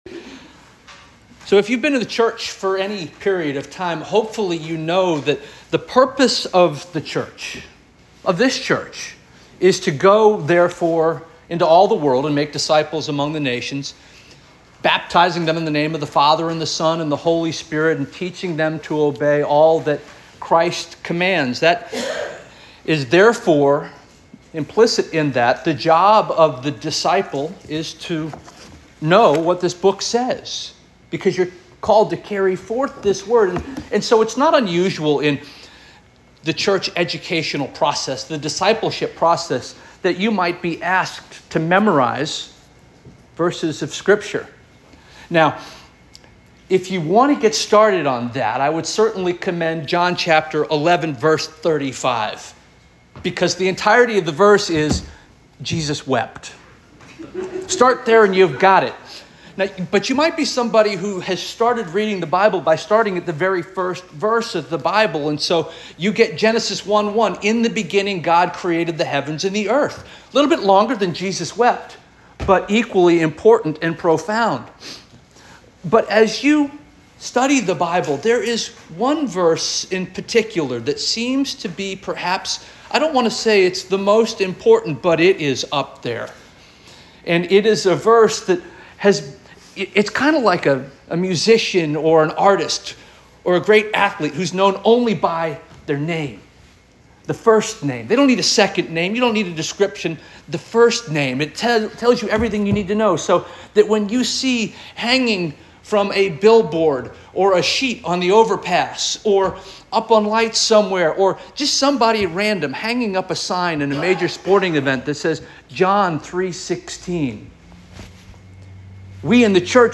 March 16 2025 Sermon - First Union African Baptist Church